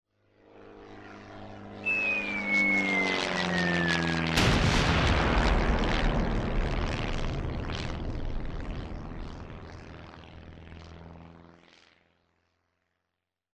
Caída cómica de una avioneta
Sonidos: Transportes